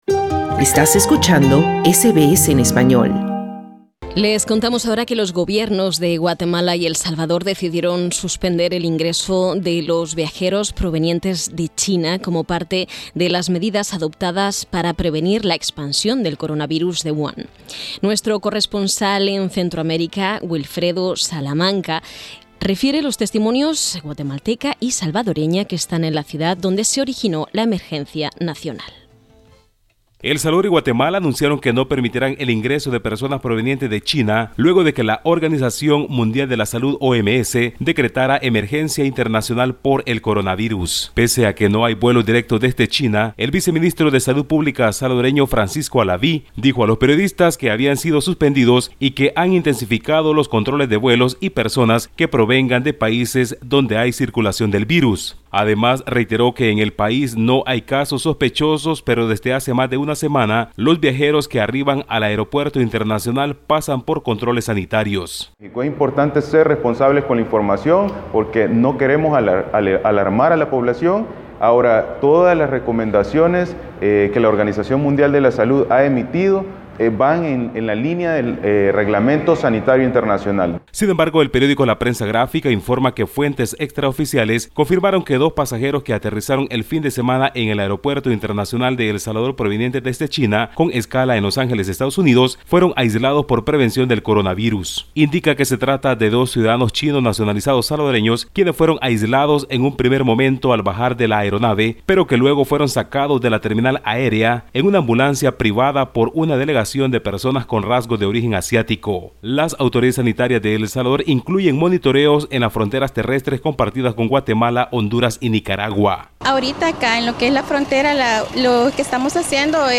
nos acerca los testimonios de una mujer guatemalteca y otra salvadoreña que se encuentran en la ciudad donde se originó la emergencia internacional.